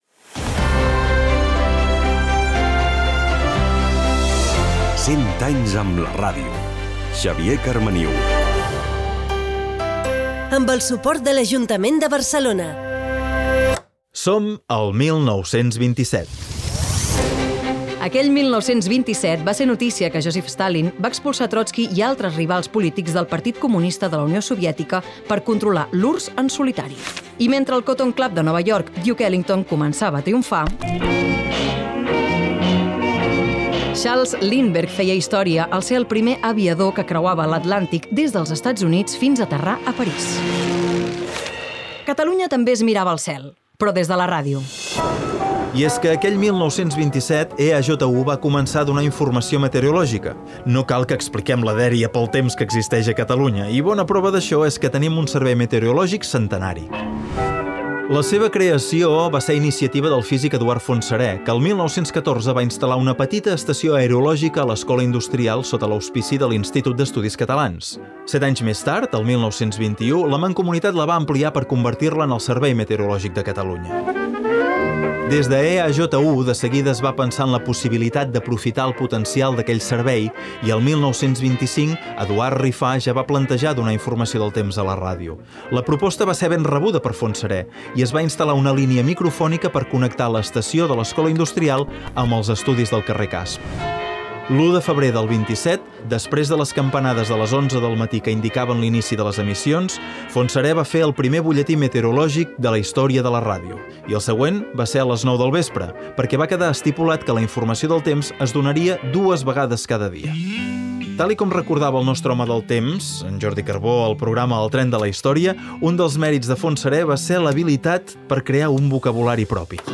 Careta del programa, fets de l'any 1927 al món.
Divulgació
Presentador/a